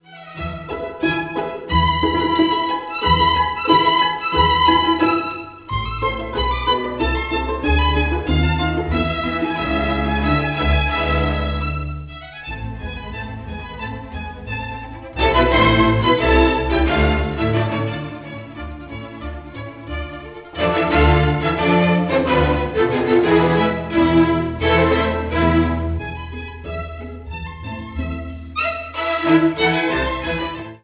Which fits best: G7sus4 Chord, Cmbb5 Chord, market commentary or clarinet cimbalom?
clarinet cimbalom